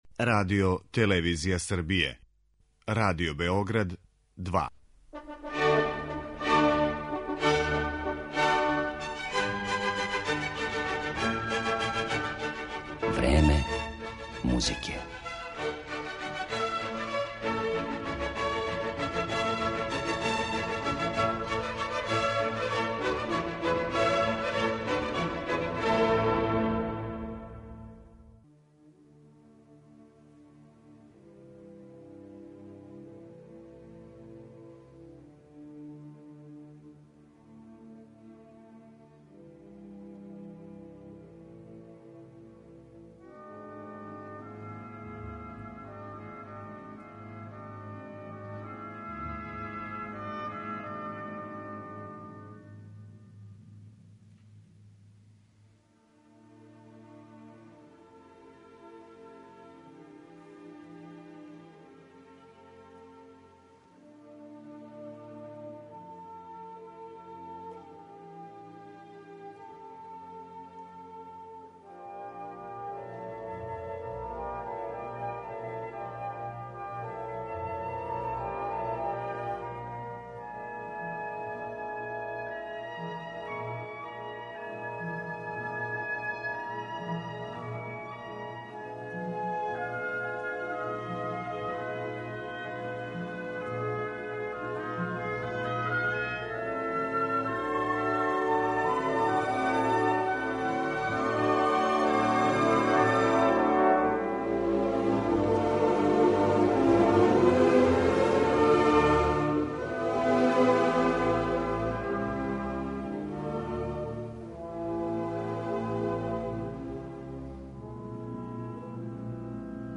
Избаране фрагменте из композиција ових аутора слушаћете у извођењу врхунских оркестара и вокалних солиста.